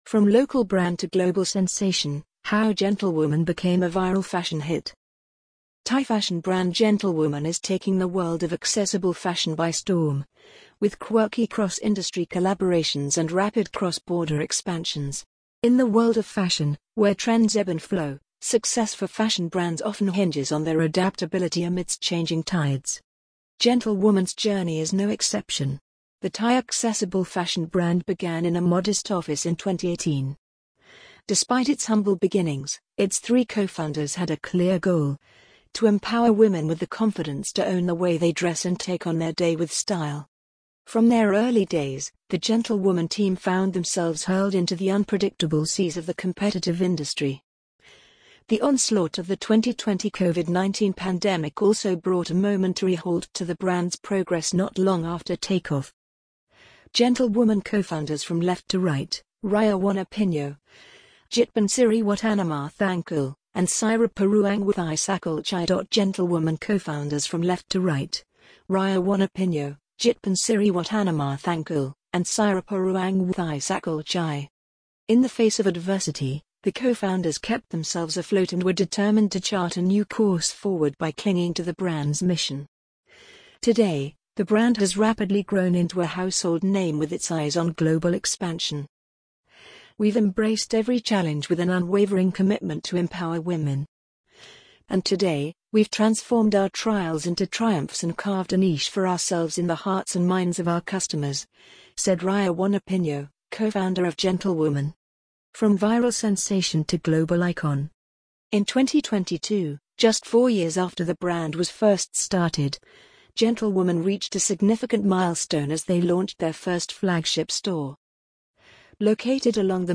amazon_polly_45982.mp3